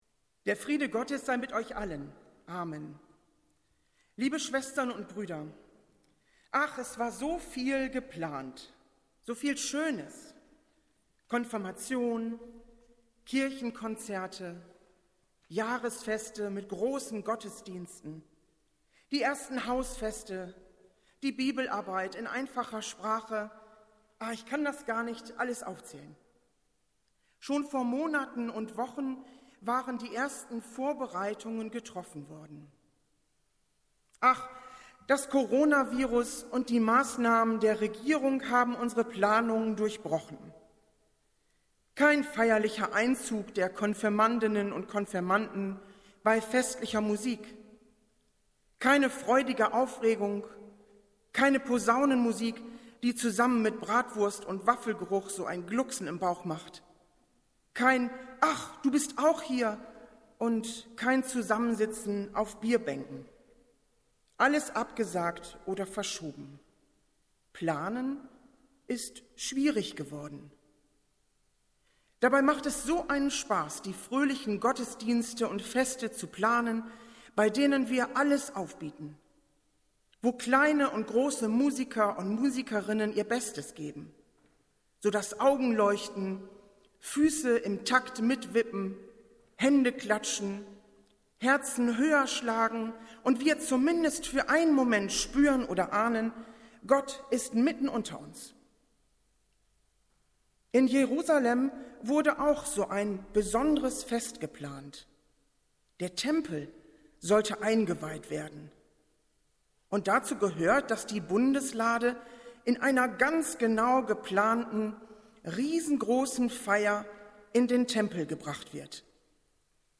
Predigt des Gottesdienstes vom 10.05.2020
In den letzten Wochen erreichten uns verstärkt Anfragen, in denen wir gebeten wurden die Gottesdienste aus der Zionskirche zum Nachhören bereitzustellen.
Wir haben uns daher in Absprache mit der Zionskirche entschlossen, die Predigten zum Download anzubieten.